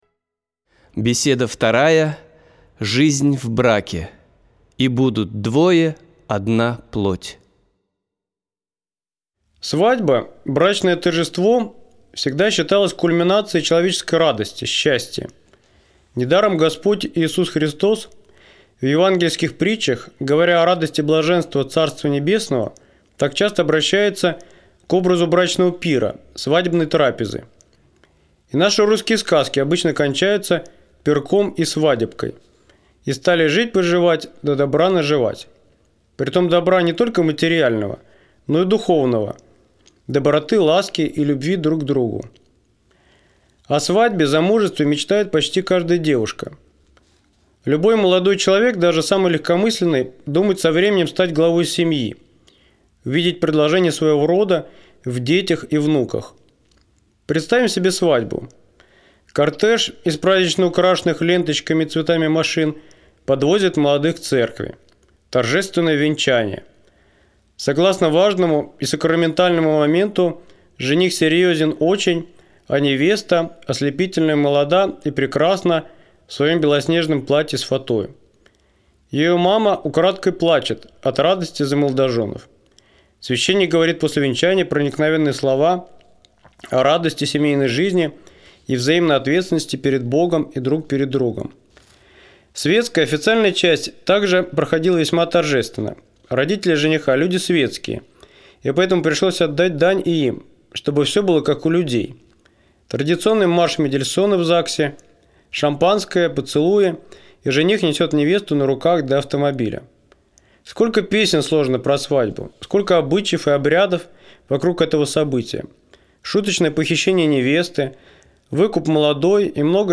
Беседа 2. Жизнь в браке.